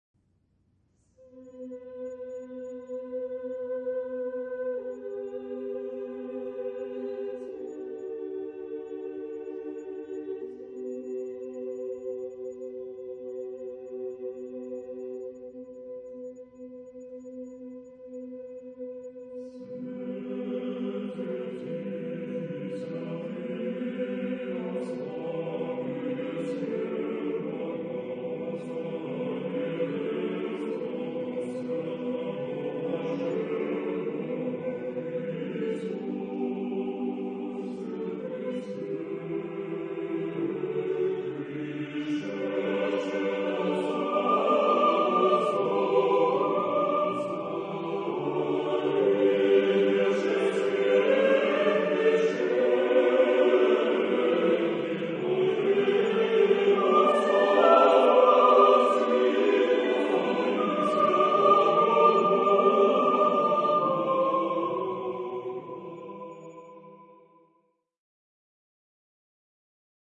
SATB (4 voices mixed) ; Choral score.
Orthodox liturgical hymn.
Genre-Style-Form: Sacred ; Orthodox liturgical hymn ; Orthodox song Mood of the piece: solemn ; majestic ; prayerful Type of Choir: SATB (4 mixed voices )
Tonality: B flat major